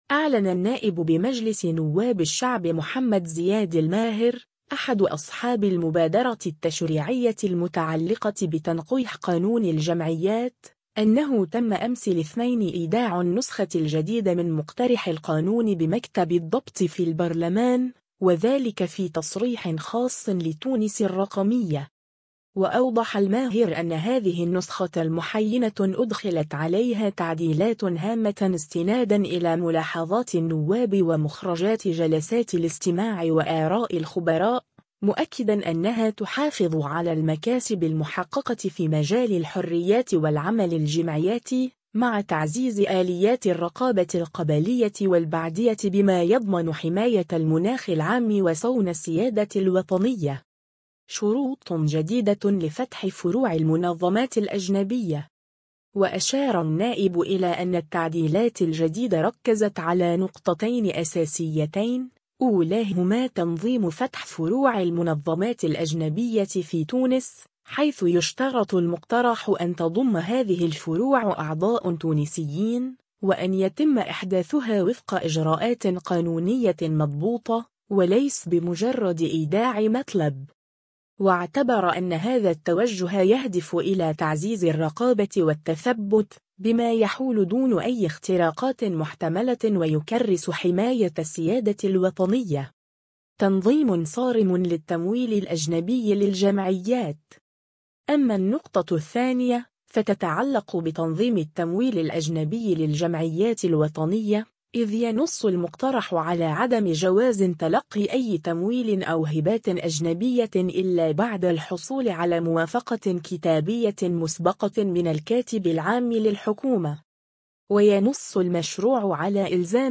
أعلن النائب بمجلس نواب الشعب محمد زياد الماهر، أحد أصحاب المبادرة التشريعية المتعلقة بتنقيح قانون الجمعيات،أنه تم أمس الاثنين إيداع النسخة الجديدة من مقترح القانون بمكتب الضبط في البرلمان، وذلك في تصريح خاص لـ”تونس الرقمية”.